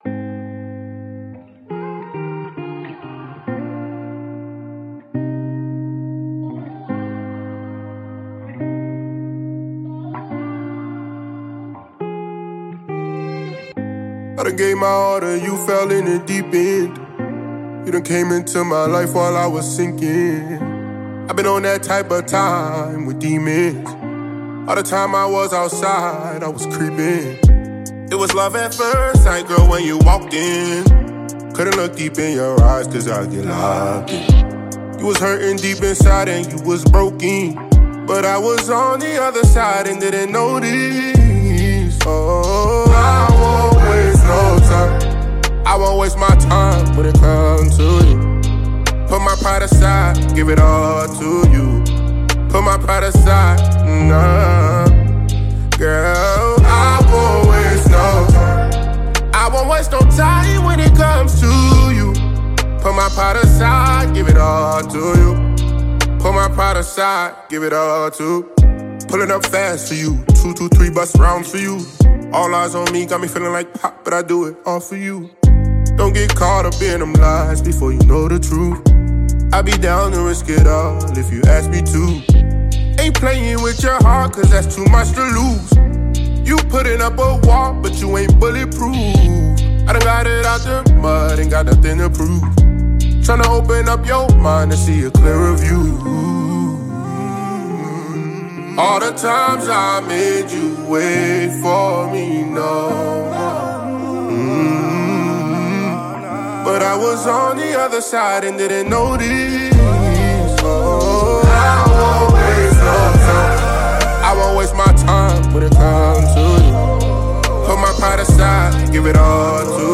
Award winning RnB singer